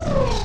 small_enemy_destroyed.wav